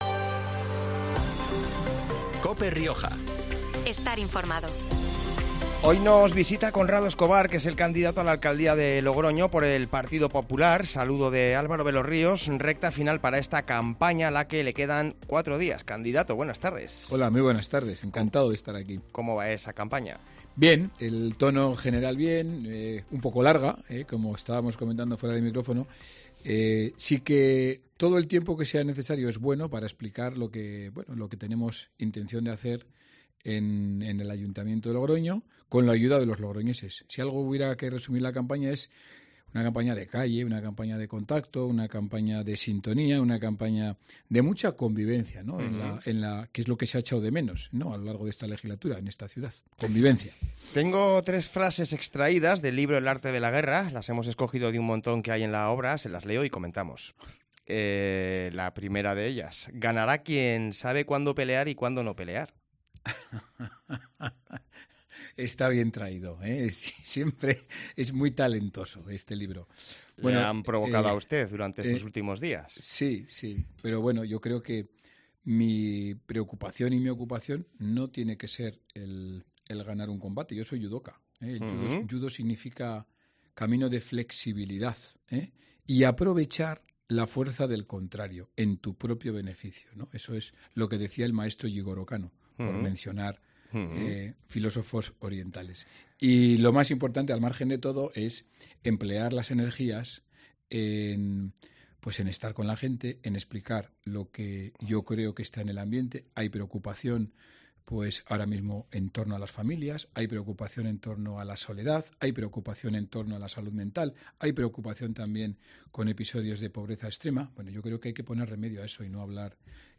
En los micrófonos de COPE Rioja, Conrado Escobar, candidato del PP a la Alcaldía de Logroño.